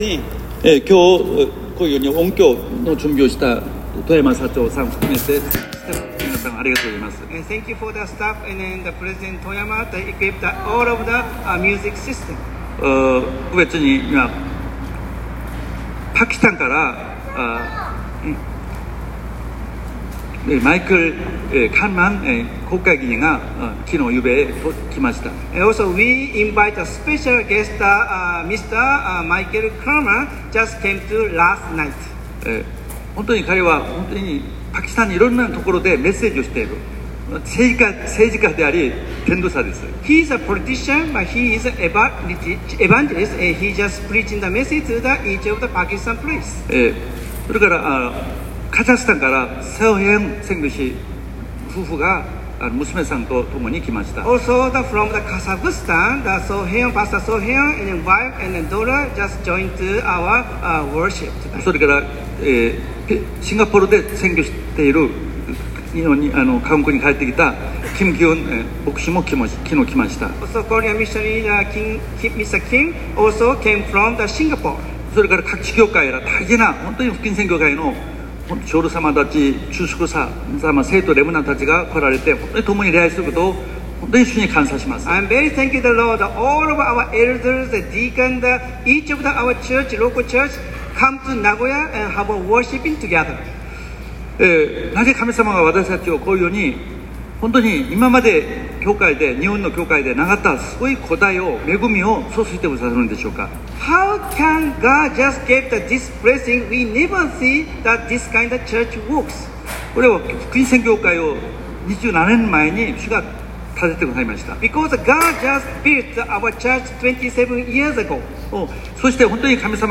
主日二部 / Gospel Mission Church 2nd Service